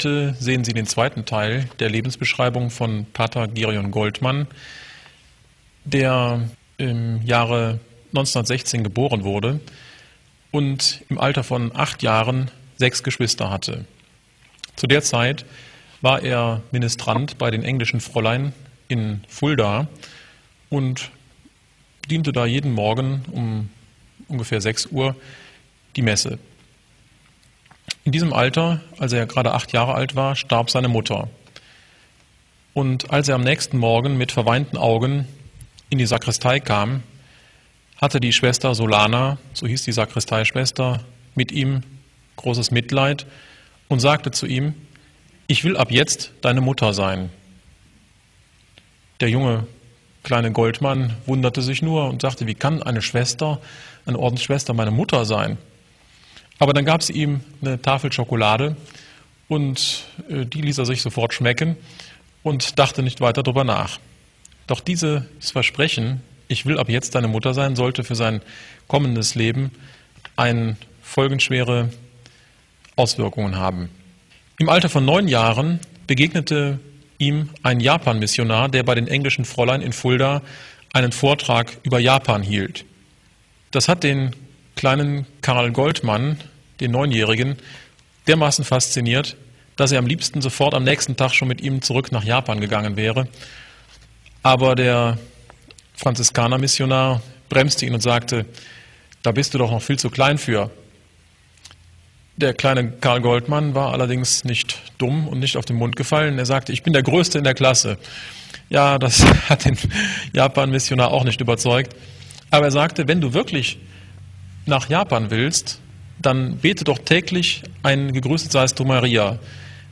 Katholische Predigten & Vorträge Podcast